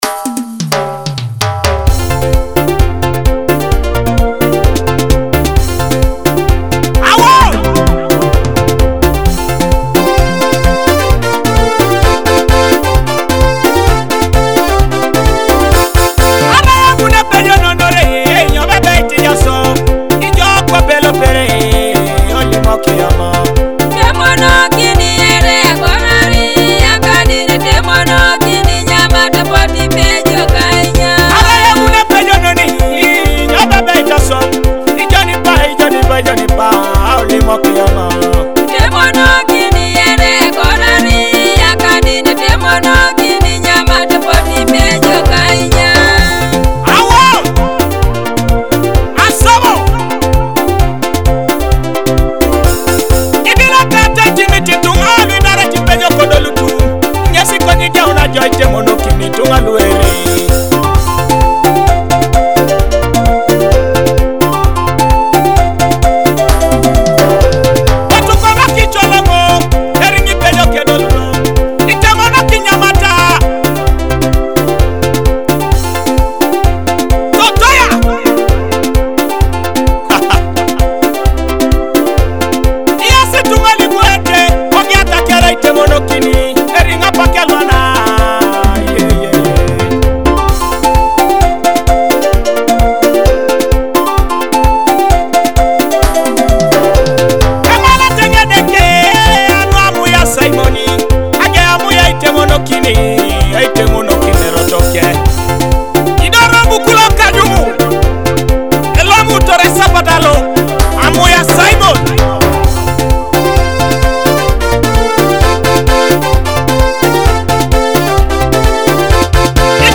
featuring Akogo and Adungu rhythms.